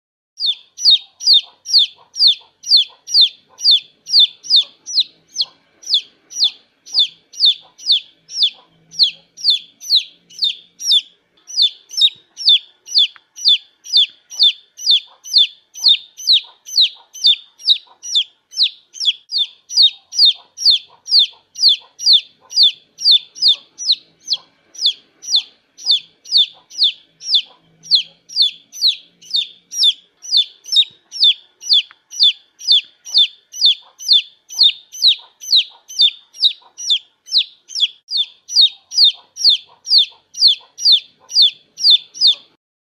Tiếng Gà con kêu mp3